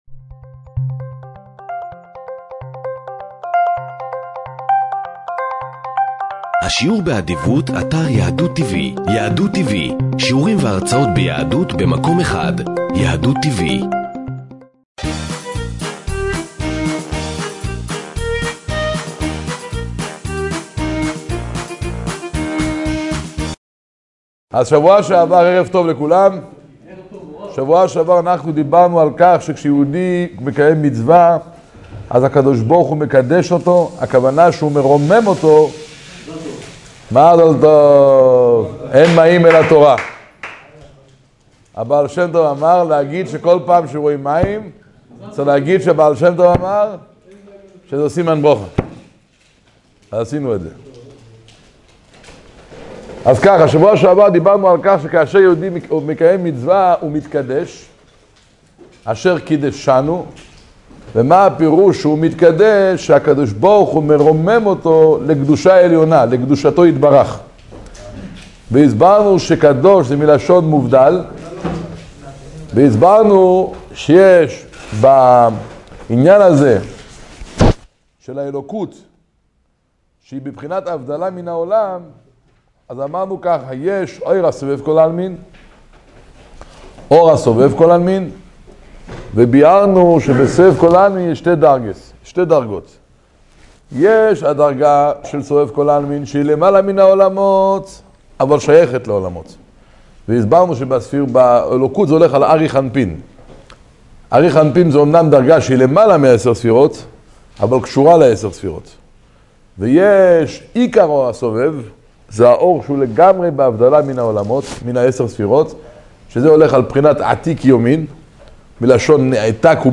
שיעור תניא